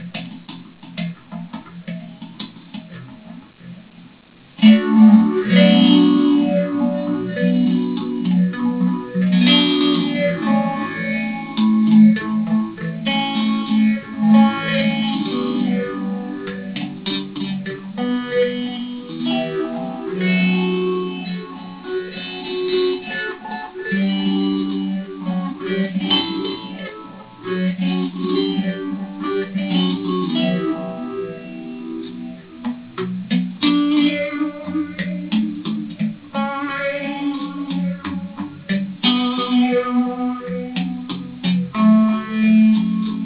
These riffs were recorded in mono to minimize file size.